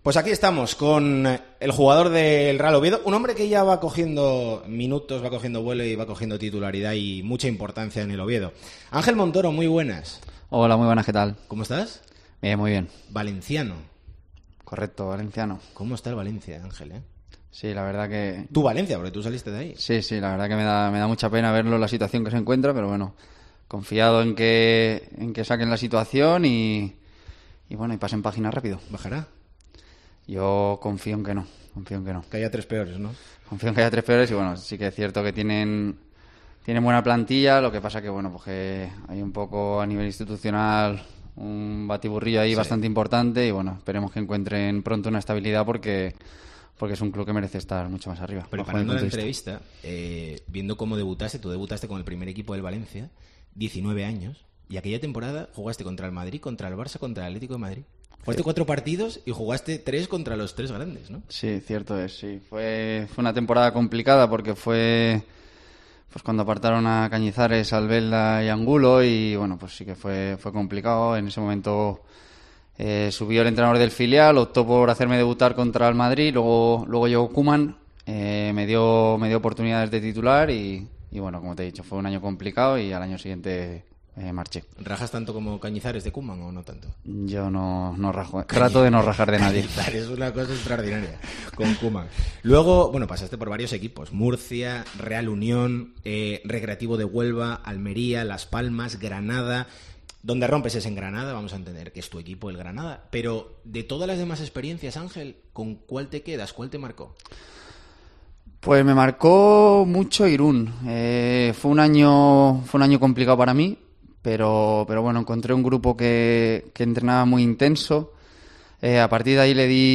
Nos atiende Ángel Montoro en Deportes COPE Asturias en un momento clave de la temporada, con muchos temas encima de la mesa.